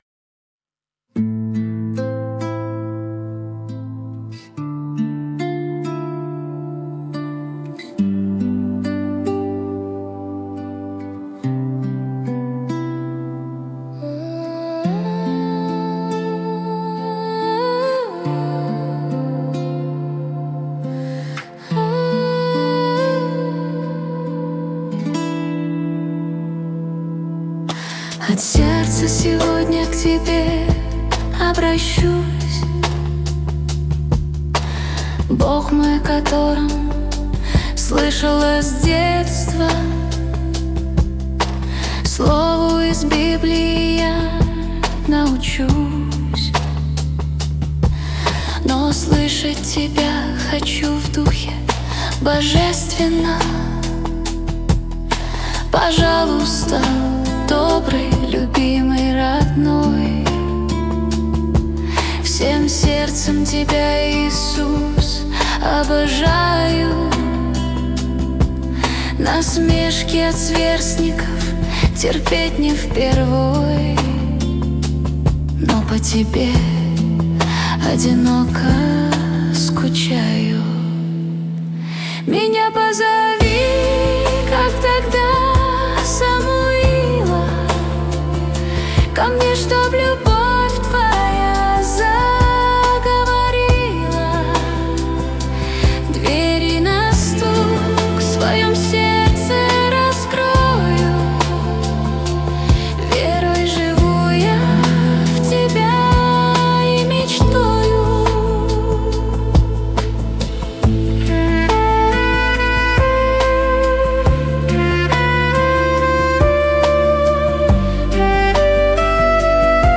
песня ai
255 просмотров 856 прослушиваний 83 скачивания BPM: 70